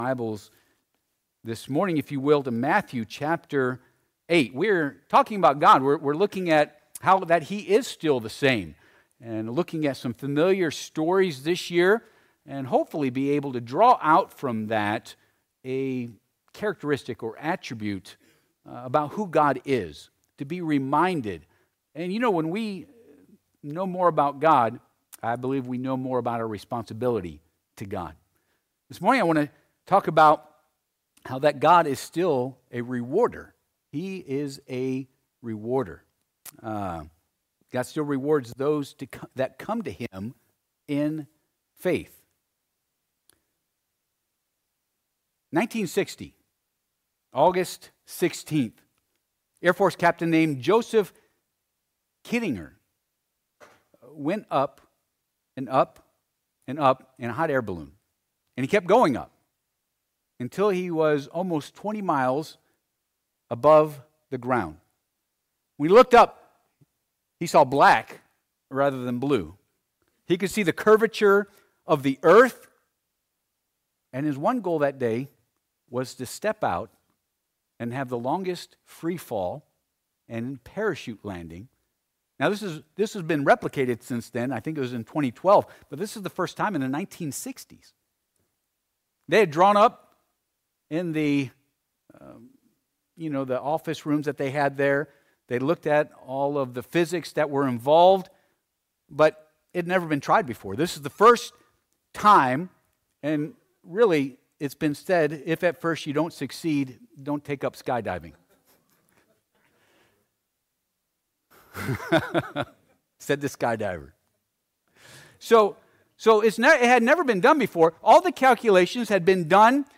Passage: Matt. 8:5-13 Service Type: Sunday AM